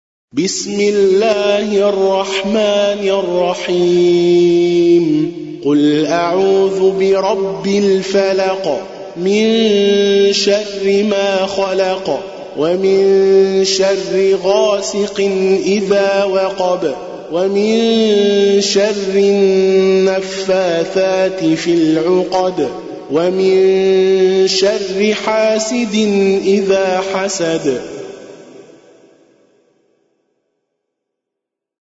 المصحف المرتل (برواية شعبة وحفص عن عاصم)
جودة عالية